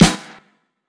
Snares
WRTTYE_SNR.wav